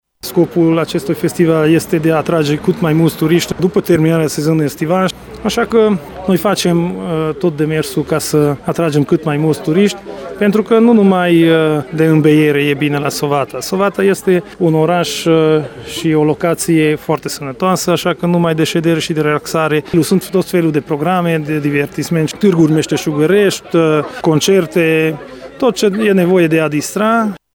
Primarul oraşului Sovata Fulop Laszlo: